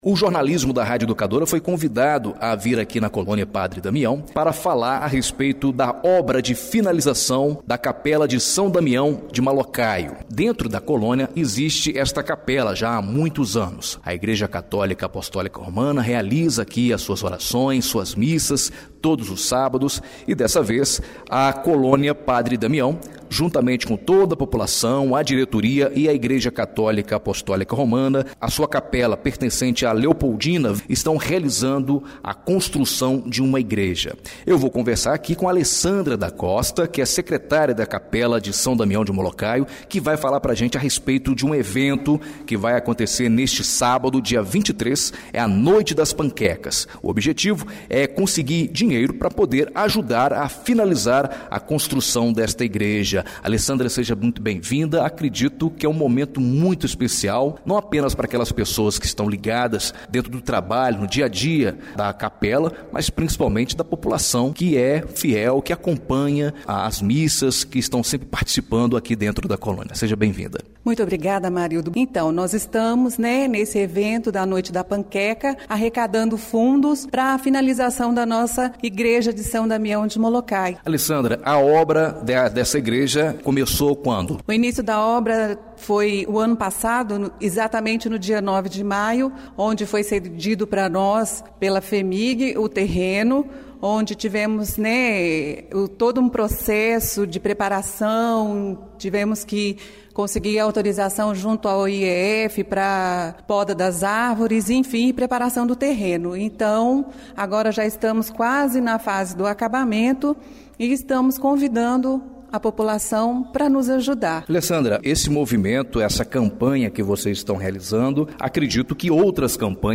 Entrevista exibida na Rádio Educadora Ubá – MG